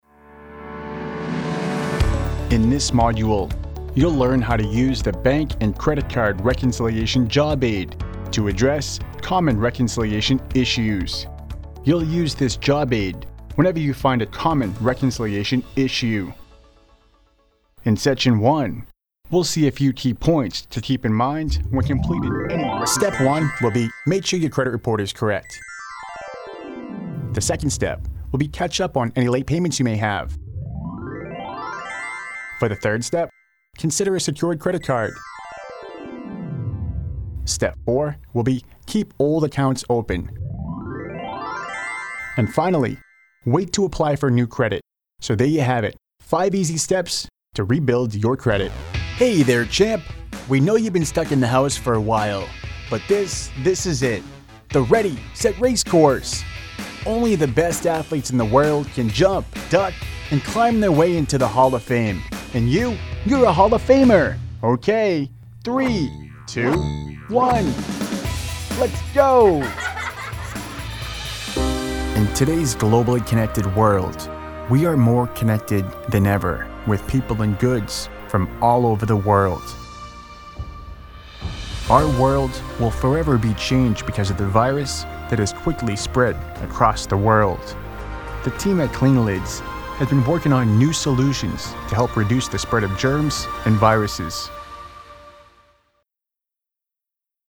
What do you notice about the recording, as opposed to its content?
Equipment Sennheiser MK416, GXL2200, STEINBERG UR22MKII, 1202VLZ PRESONUS STUDIO 1